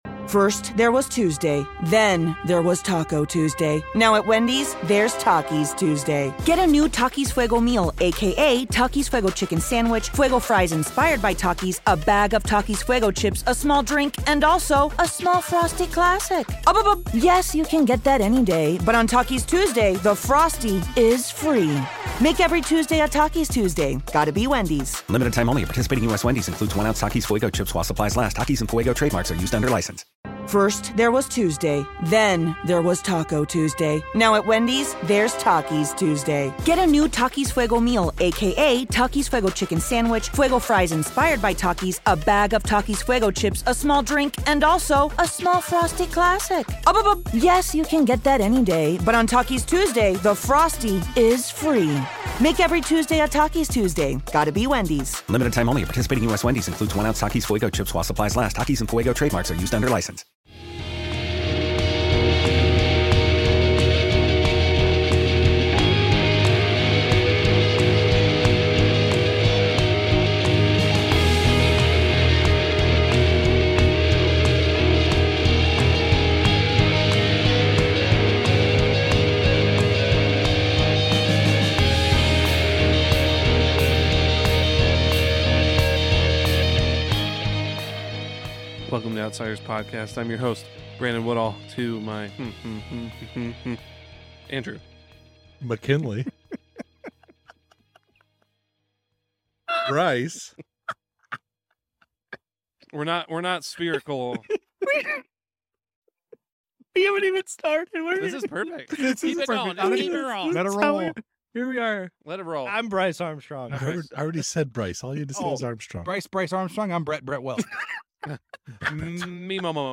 Worship Interview Part 1